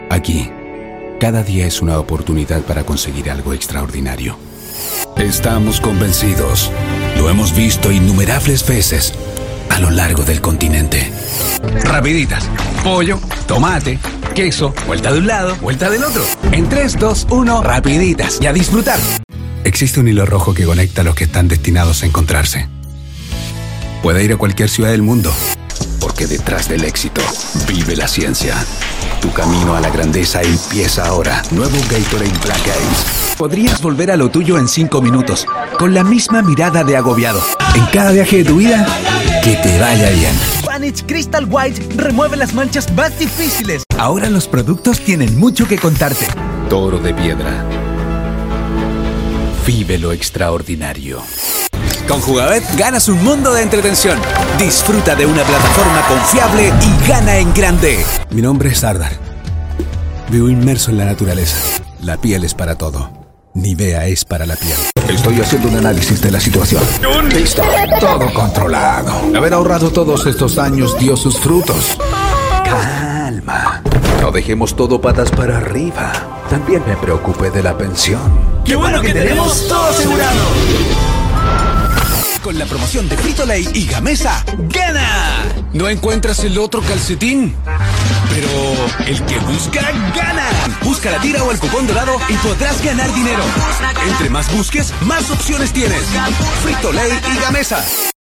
Dulce y cercana. Amable, sensual y rotunda.
Gracias a la interpretación, la voz puede ser cercana y cariñosa o rotunda y dura, dependiendo de la necesidad del texto....